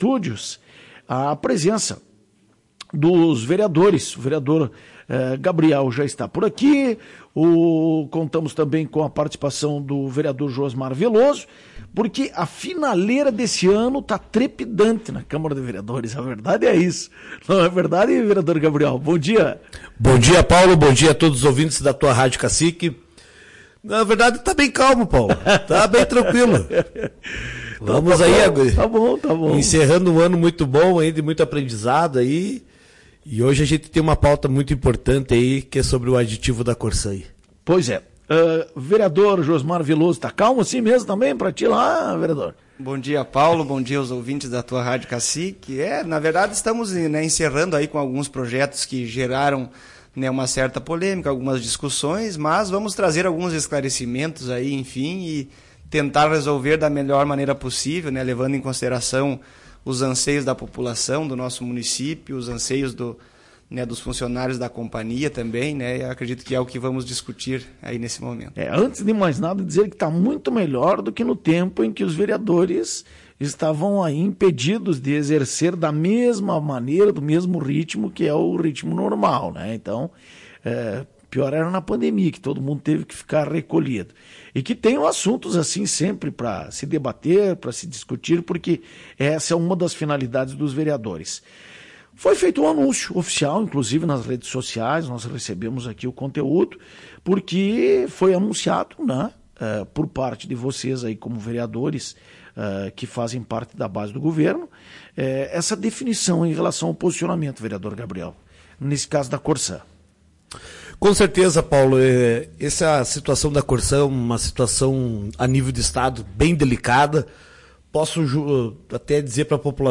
Em entrevista ao Programa Conectado, na manhã desta quinta, os vereadores Gabriel Vieira e Josmar Veloso estiveram falando dessas definições, e expectativa para a sessão extraordinária de hoje.